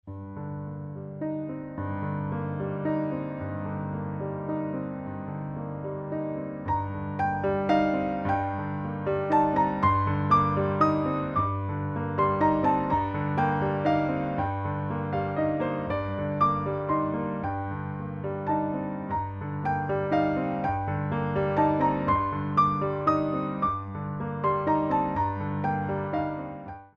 3/4 - 64 with repeat
4 Count introduction included for all selections